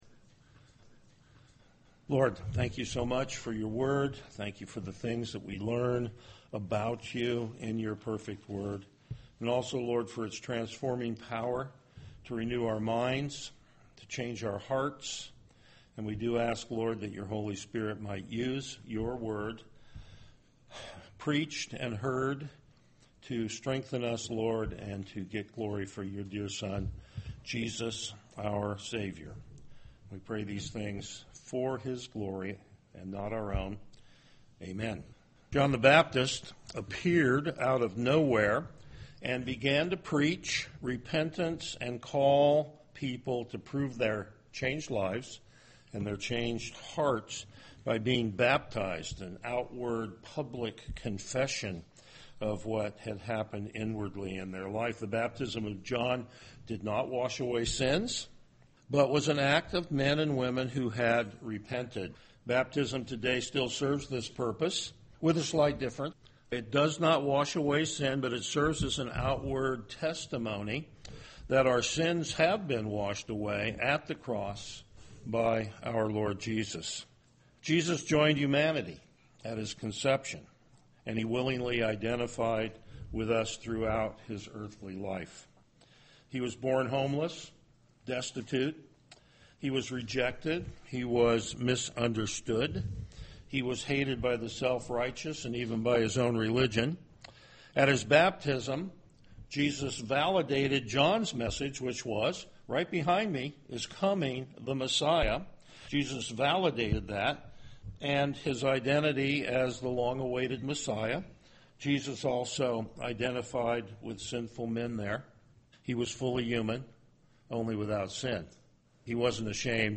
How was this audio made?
Passage: Matthew 5:1-3 Service Type: Morning Worship